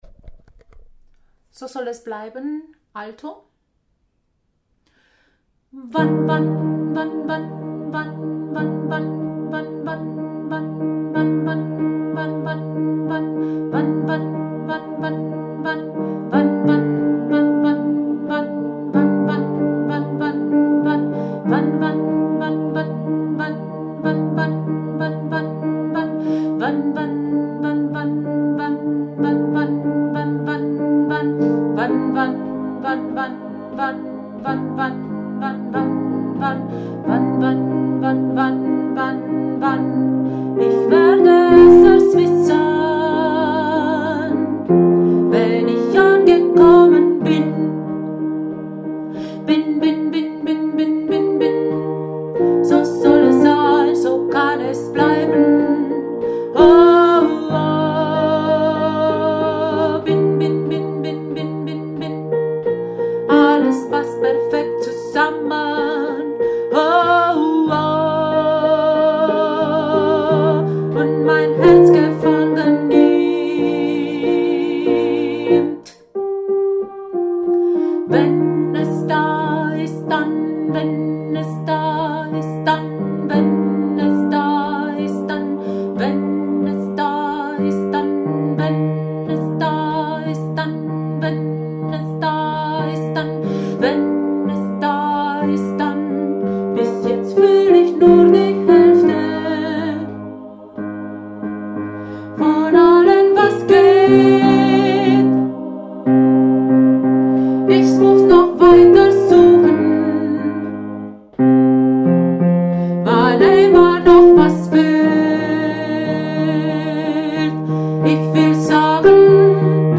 So soll es sein, so kann es bleiben – Alto